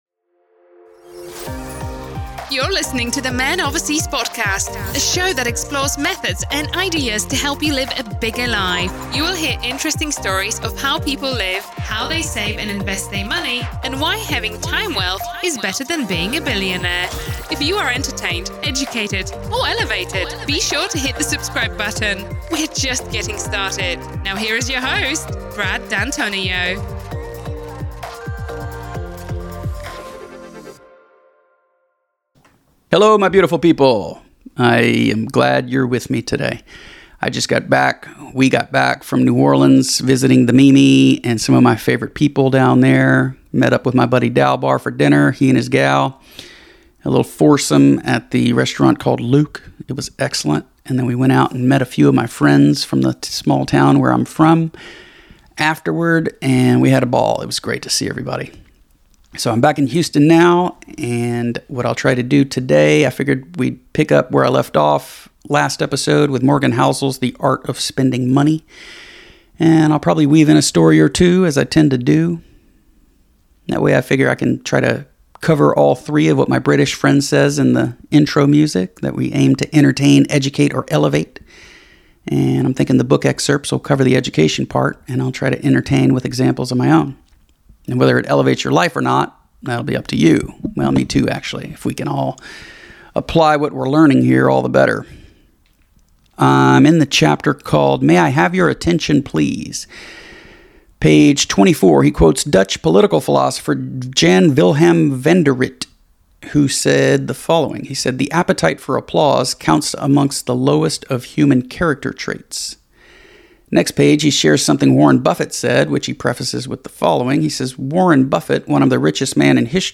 This week’s solo episode I pick back up with Morgan Housel’s The Art of Spending Money.